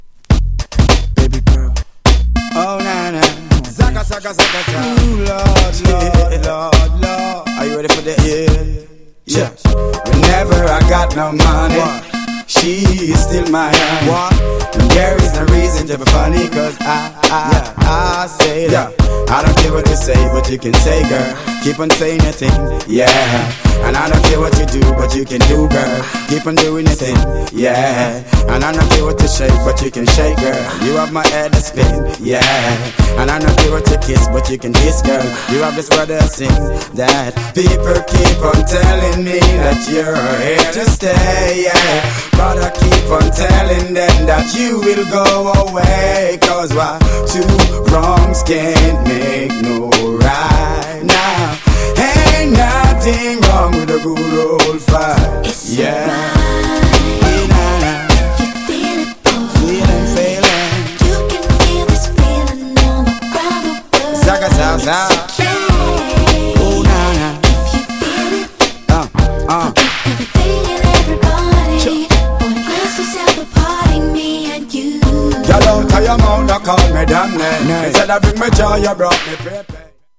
GENRE R&B
BPM 96〜100BPM